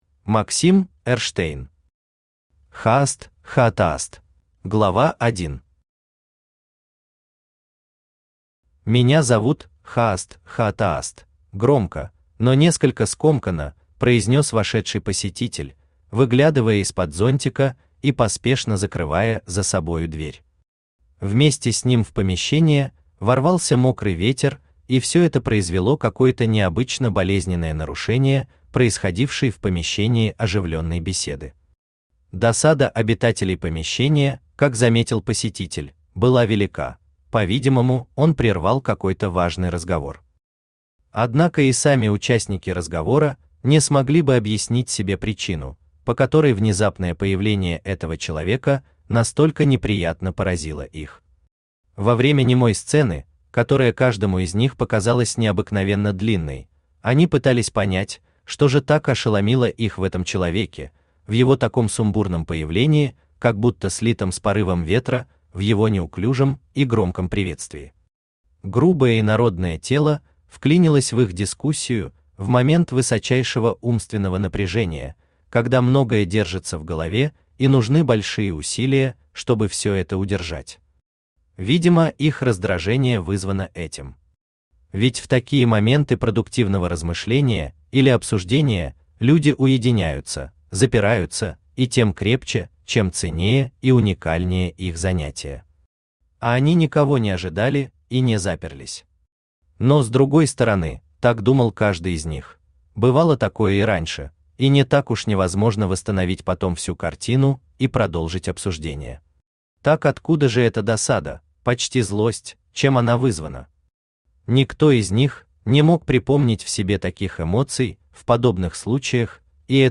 Aудиокнига Хааст Хаатааст Автор Максим Борисович Эрштейн Читает аудиокнигу Авточтец ЛитРес.